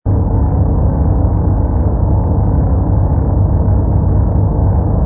rumble_ci_fighter.wav